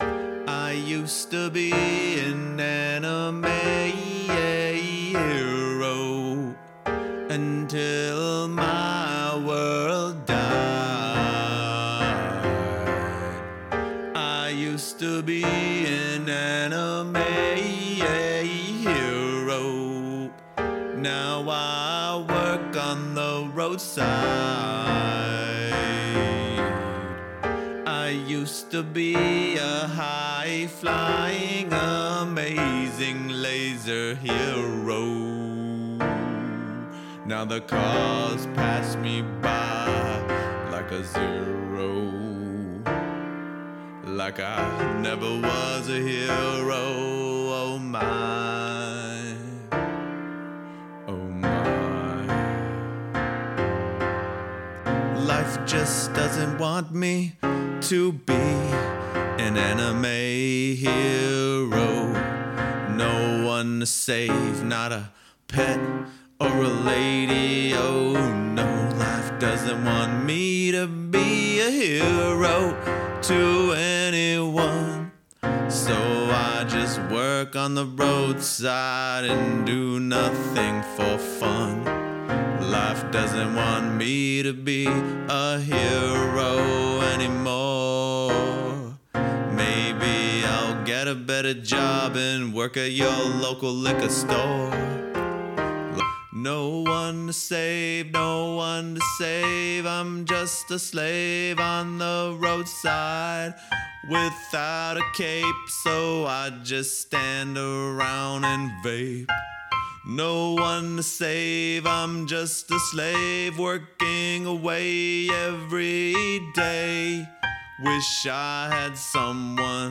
crappy prototype version